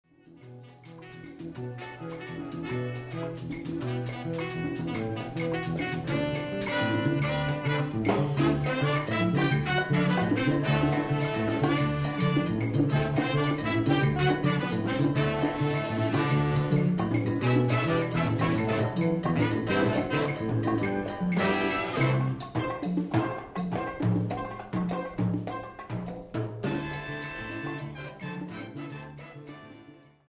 Category: little big band
Style: mambo
Solos: open